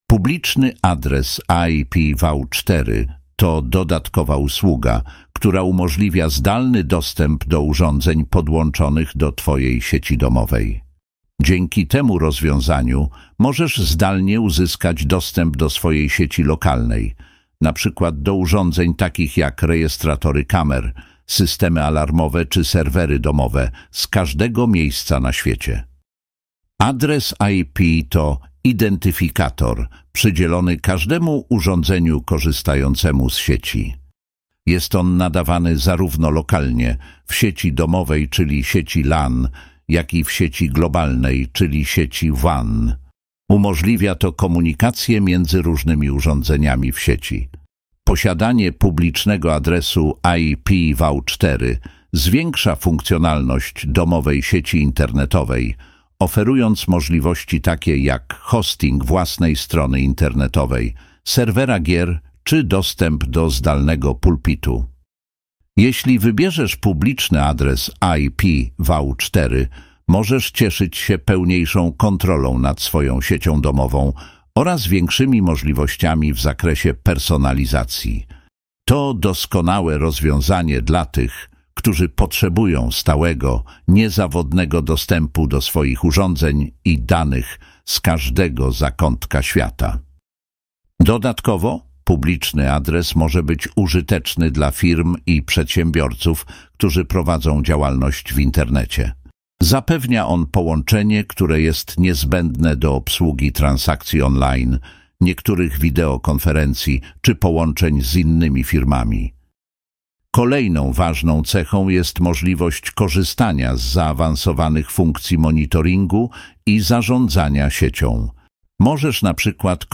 publiczny-adres-ipv4-lektorai.mp3